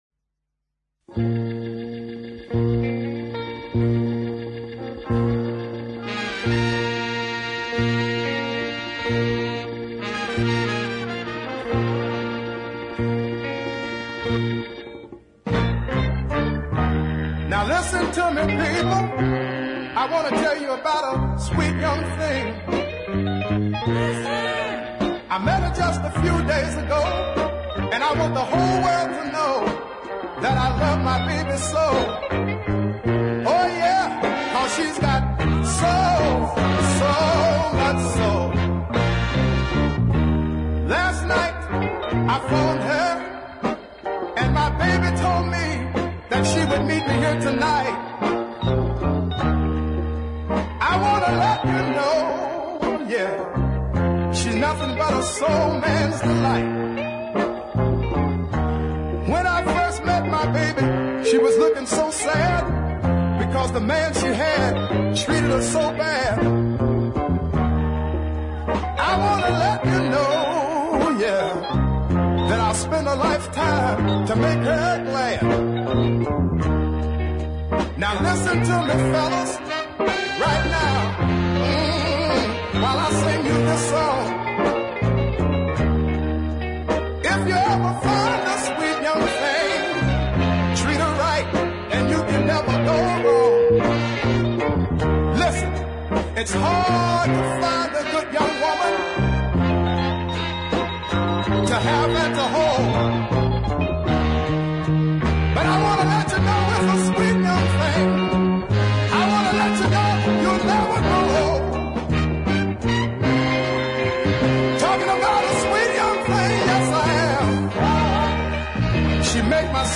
The backing track sounds just great